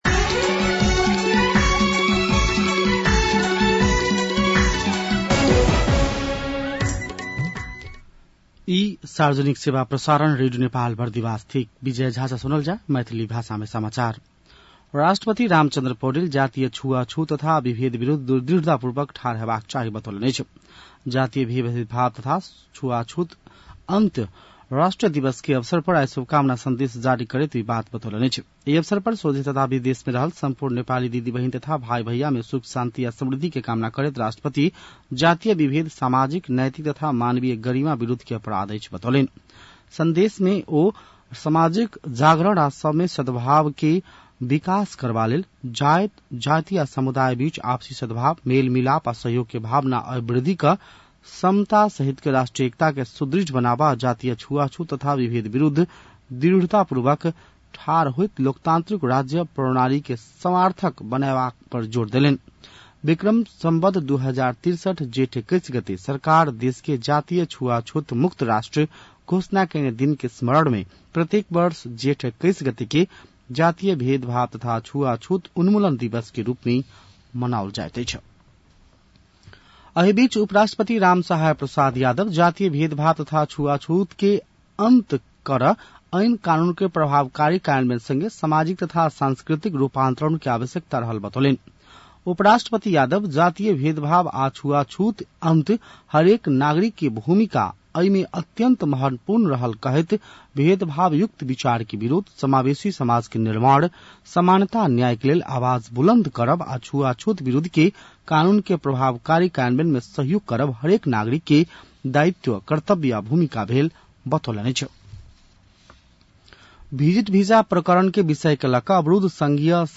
An online outlet of Nepal's national radio broadcaster
मैथिली भाषामा समाचार : २१ जेठ , २०८२